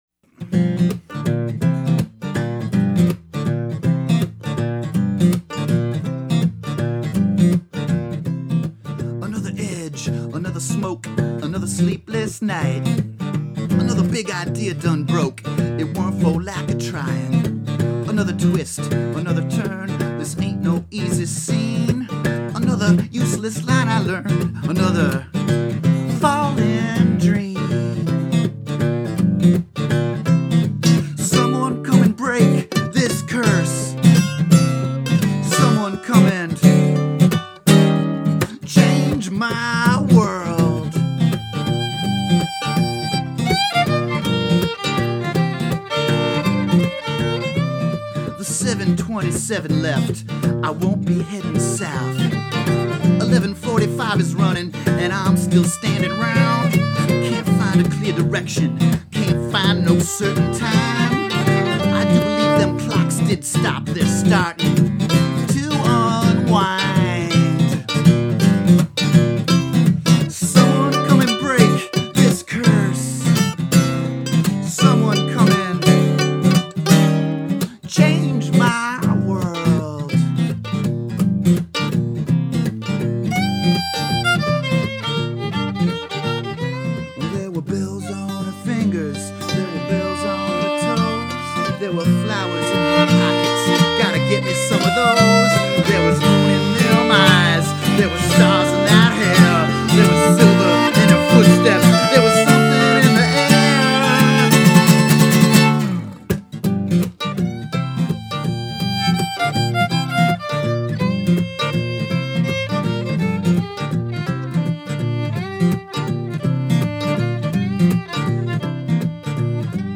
Guitar and vocals
Mandolin, fiddle, and vocals